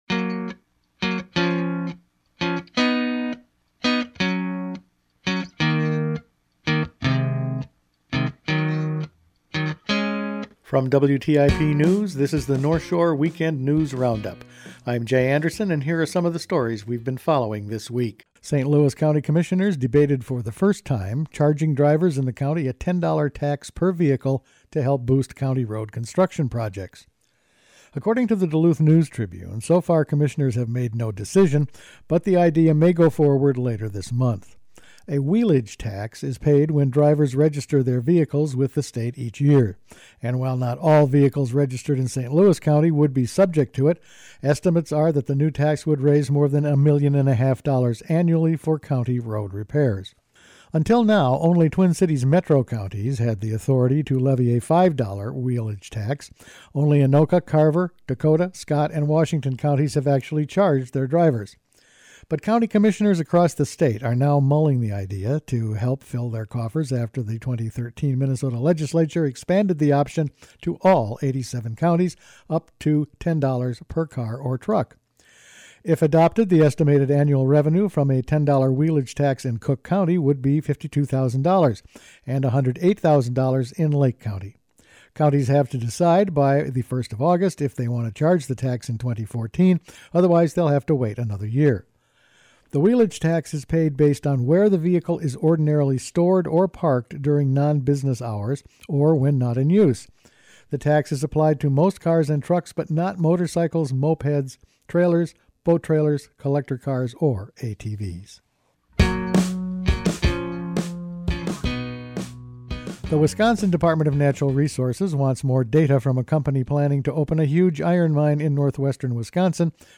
Each week the WTIP news staff puts together a roundup of the news over the past five days. The state of Superior fish, collared bears, Apostle Island actions, a resort fire and discussion of a “wheelage tax”…all in this week’s news.